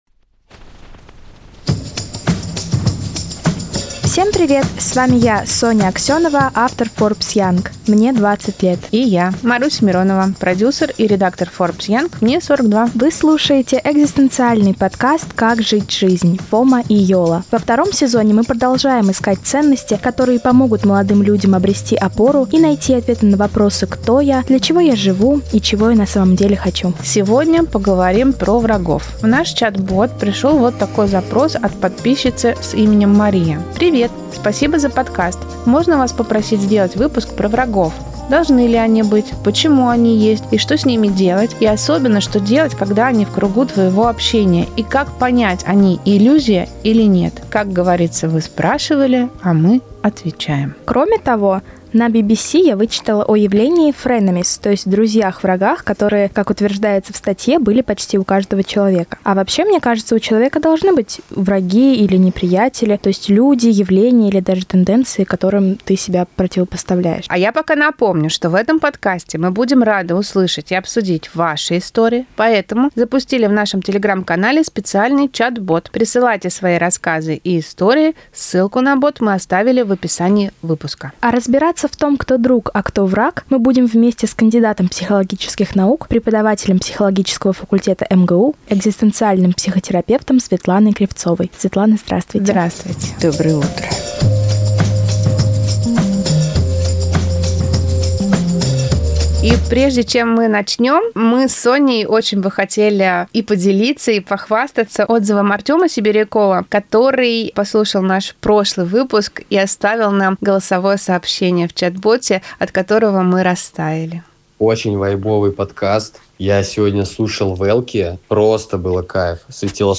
В этом выпуске слушаем и разбираем истории подписчиков Forbes Young.